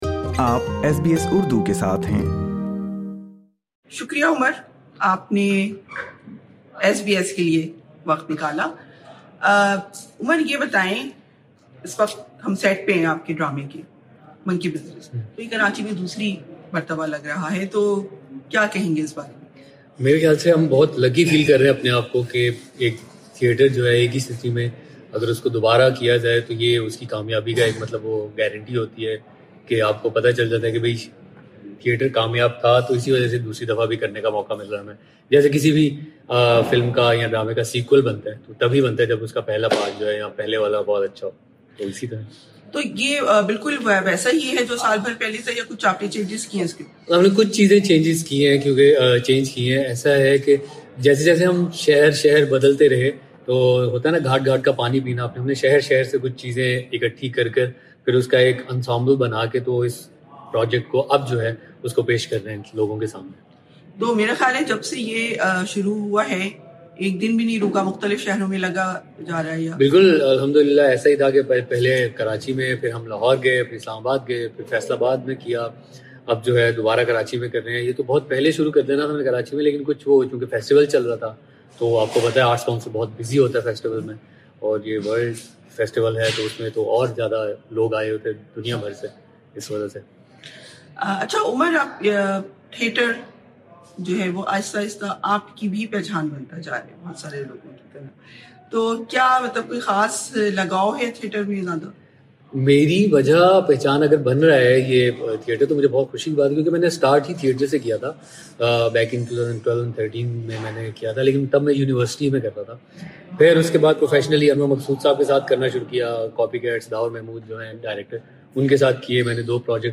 نوجوان اداکار عمر عالم کی ایس بی ایس کے ساتھ خصوصی گفتگو